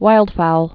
(wīldfoul)